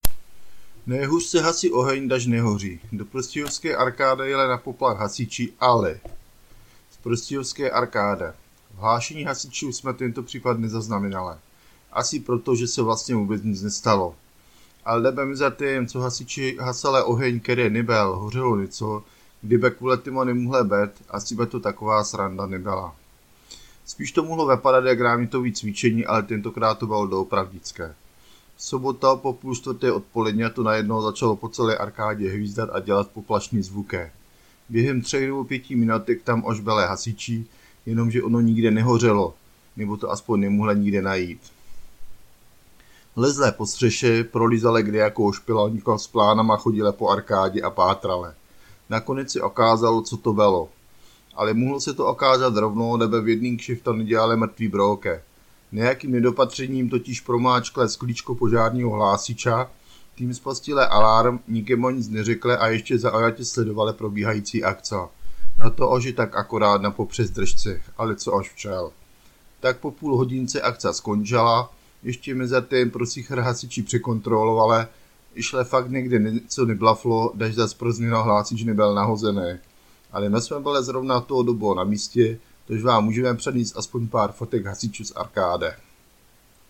V sobotô po pui štvrté odpoledňa to najednó začalo po celé Arkádě hvizdat a dělat poplašny zvukê.
hasiči-arkáda.mp3